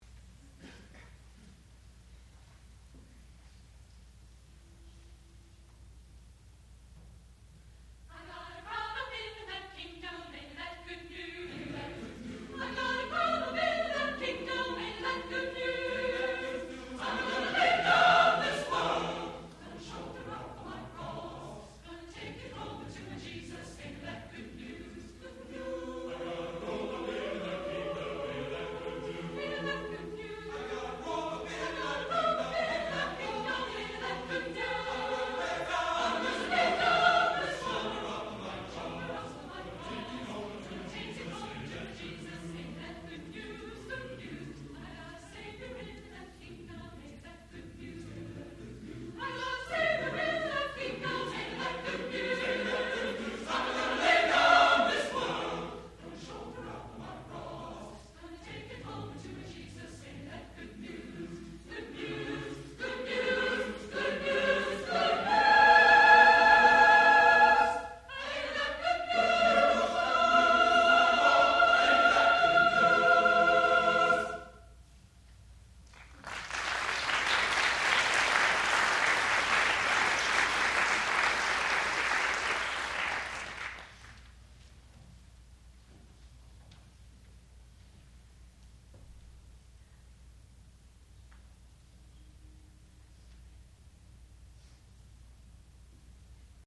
sound recording-musical
classical music